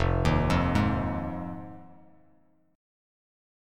Fm7 Chord
Listen to Fm7 strummed